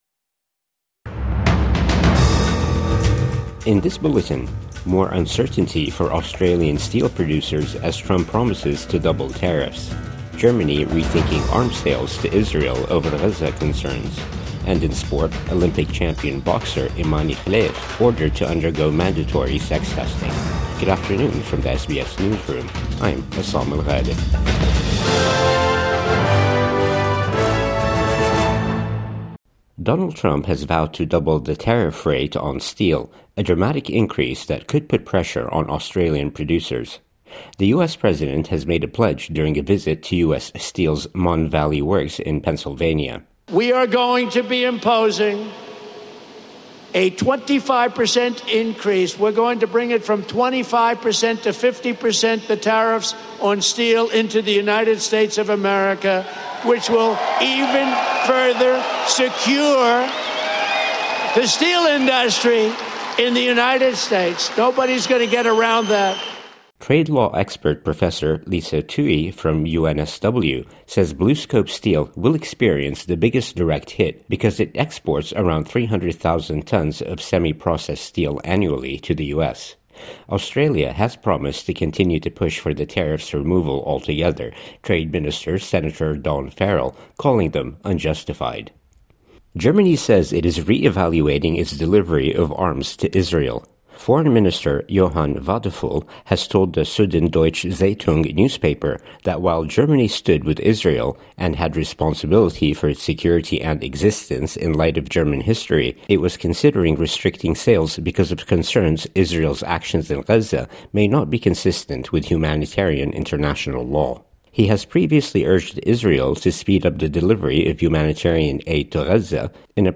More uncertainty for Australian steel producers as Trump doubles tariffs | Midday News Bulletin 31 May 2025